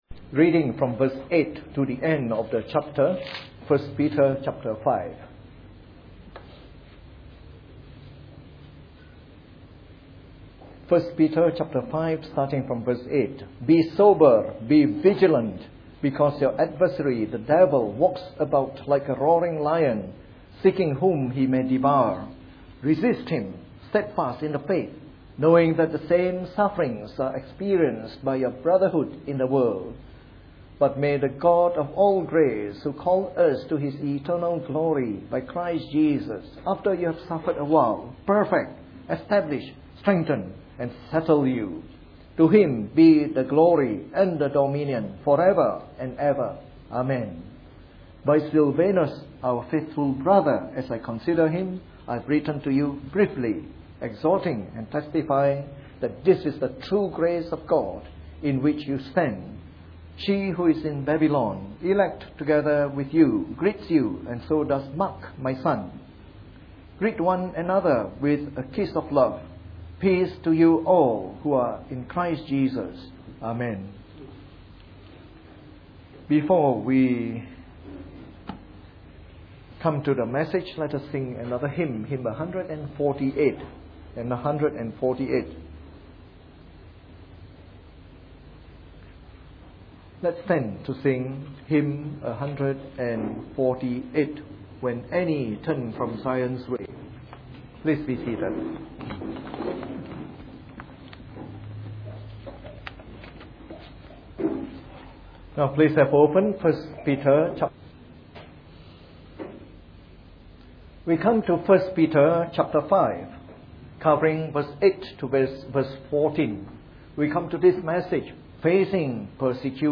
Part of our series on “The Epistles of Peter” delivered in the Evening Service.